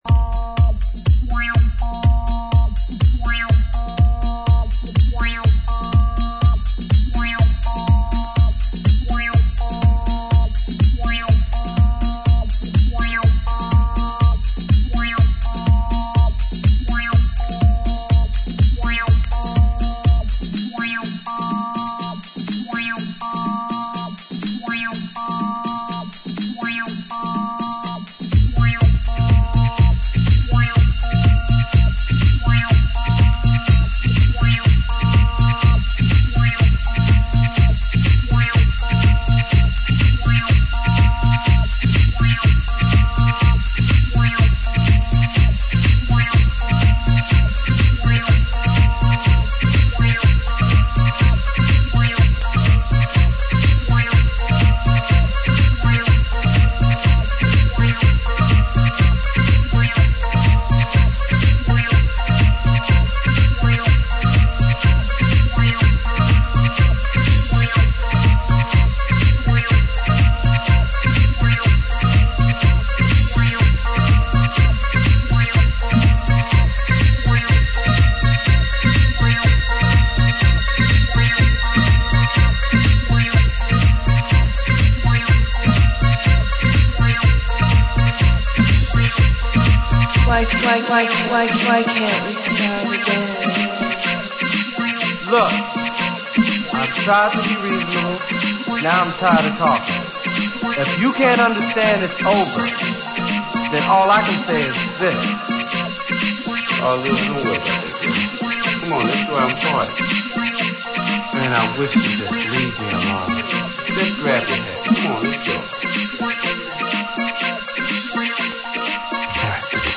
ジャンル(スタイル) HOUSE / HOUSE CLASSIC / RE-EDIT